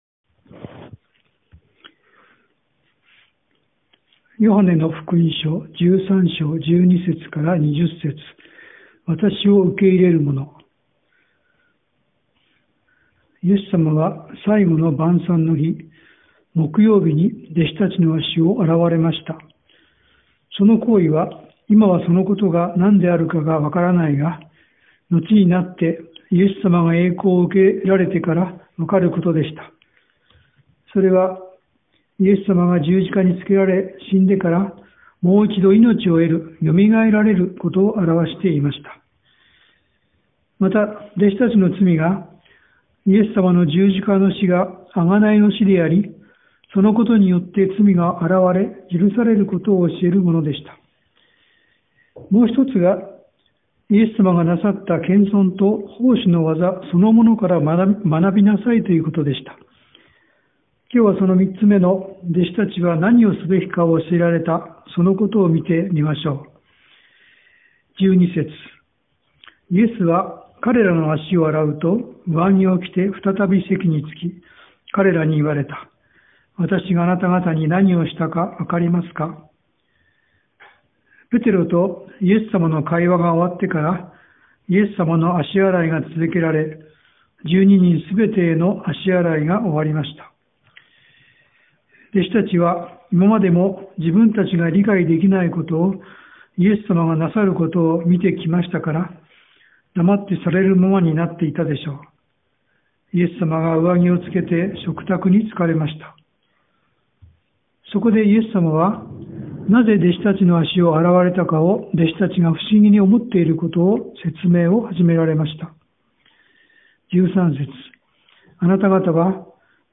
Sermon
2022年 4月24日 主日礼拝 説教 ヨハネの福音書 13章 12-20 13:12 イエスは彼らの足を洗うと、上着を着て再び席に着き、彼らに言われた。